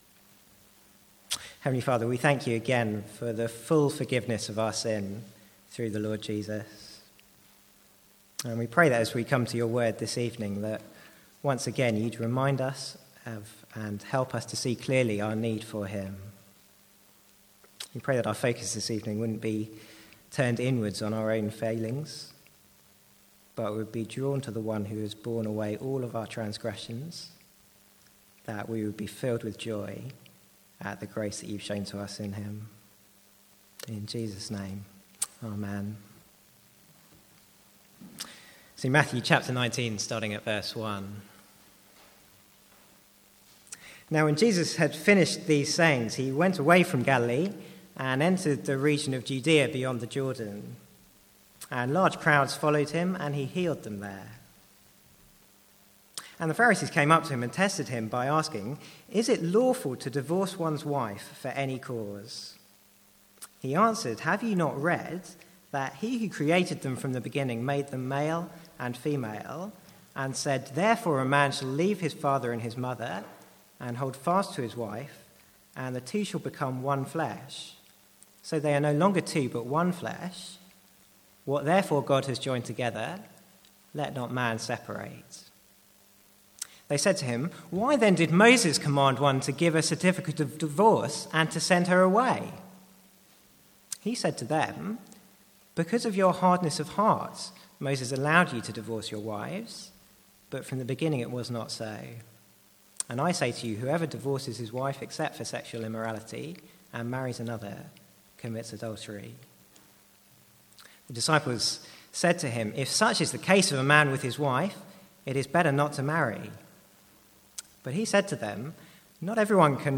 From the Sunday evening series in Matthew.